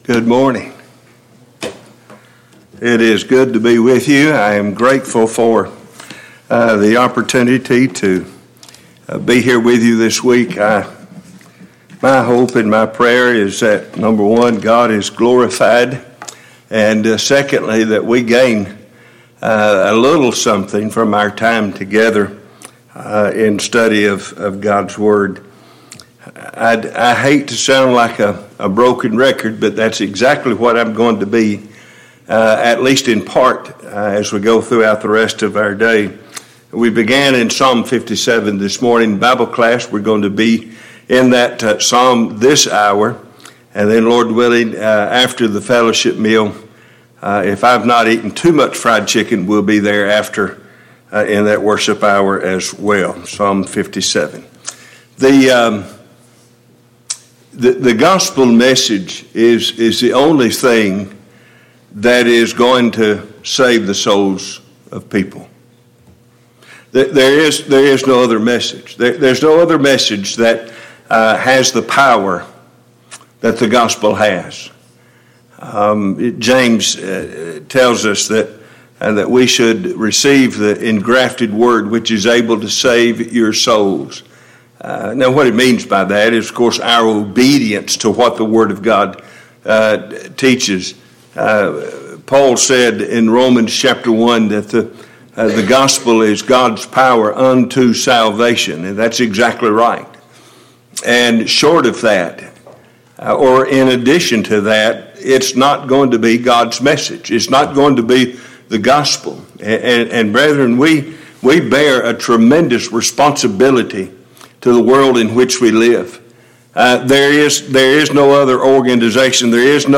Passage: Psalms 57 Service Type: Gospel Meeting